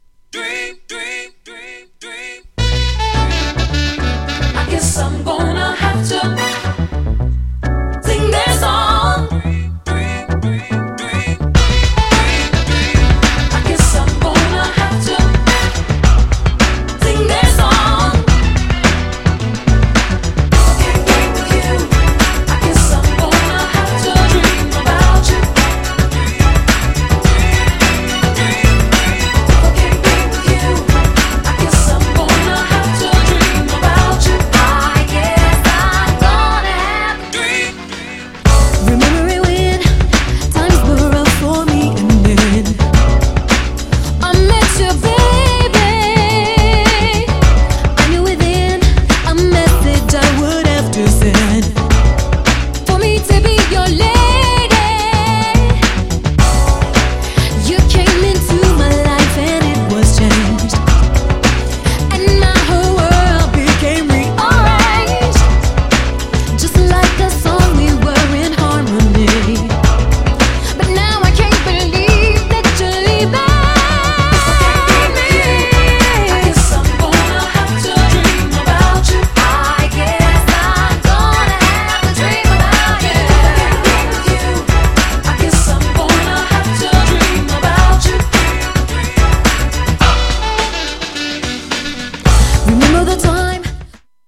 R&B MIXも!!
GENRE House
BPM 106〜110BPM